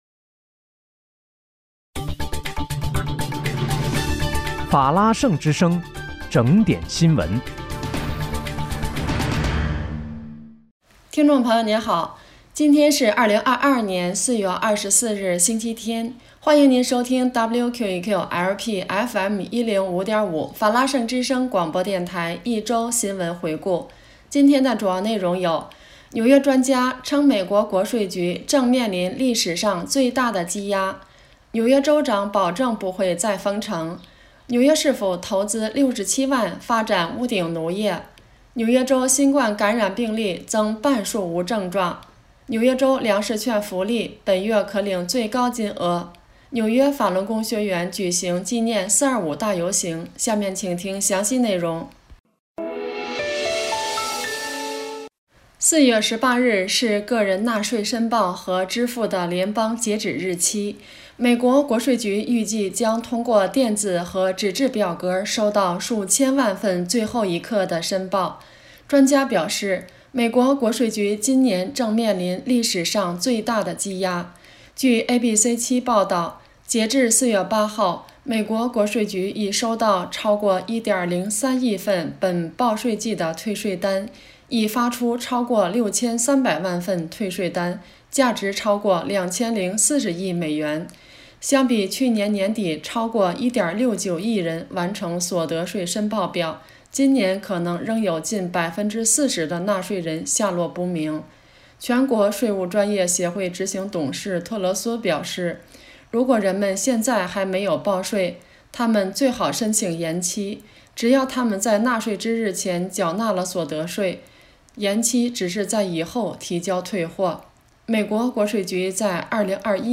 4月24日（星期日）一周新闻回顾